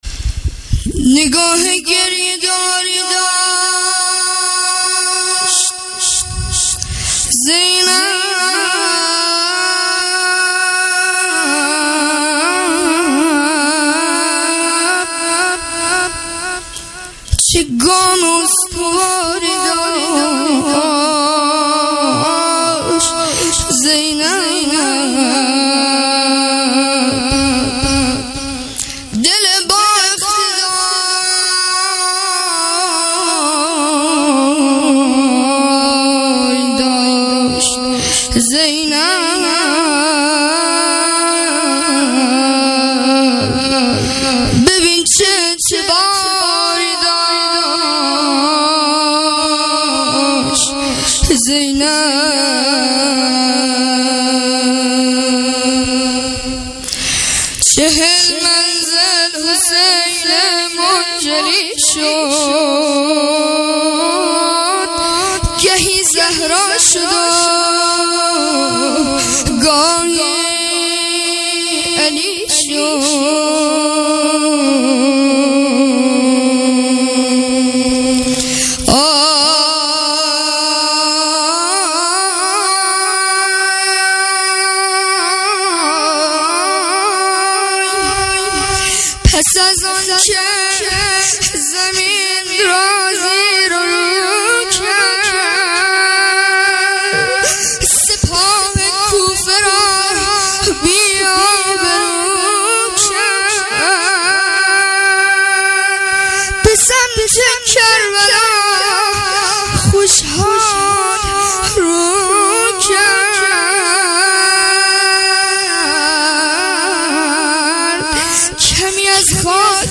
عزای اربعین حسینی۹۸